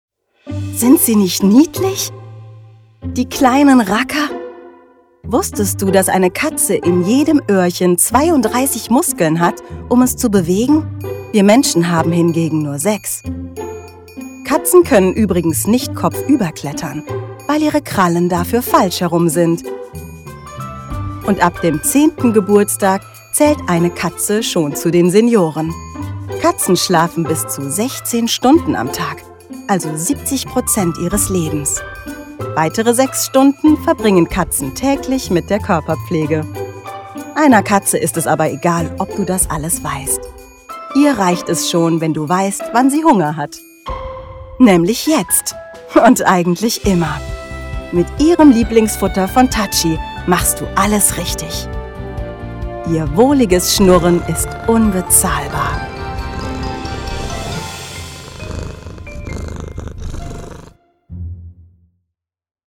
werbung-katzen-demo.mp3